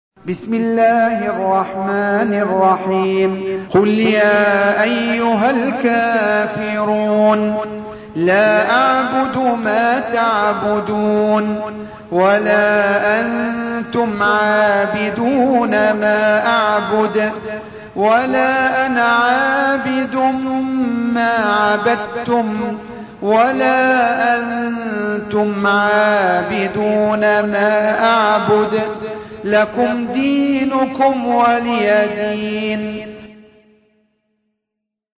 109. Surah Al-K�fir�n سورة الكافرون Audio Quran Tarteel Recitation
Surah Repeating تكرار السورة Download Surah حمّل السورة Reciting Murattalah Audio for 109. Surah Al-K�fir�n سورة الكافرون N.B *Surah Includes Al-Basmalah Reciters Sequents تتابع التلاوات Reciters Repeats تكرار التلاوات